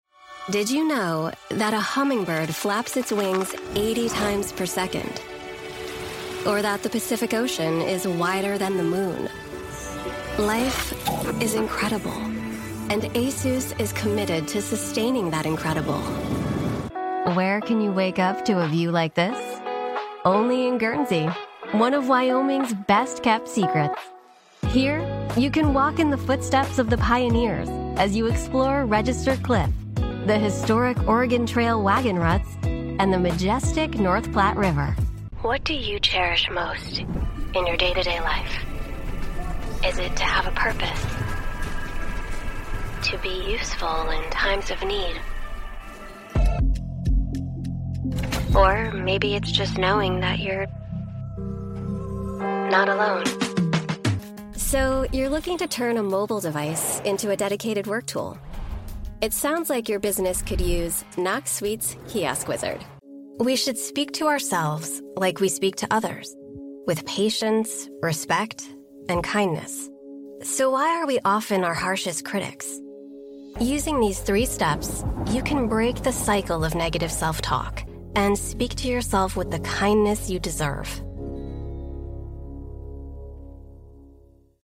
Female
American English (Native)
My voice sits in the millennial / Gen Z range – from early 20s to 40s, with a General American accent.
Dry Sample.mp3
Microphone: Sennheiser MKH416